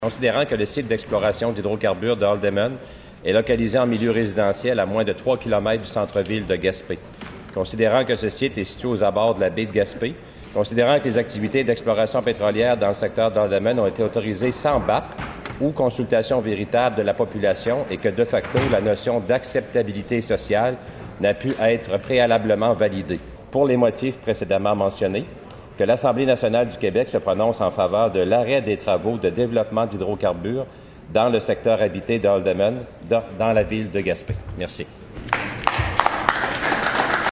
Voici un extrait du dépôt de sa motion à l’Assemblée nationale mercredi matin.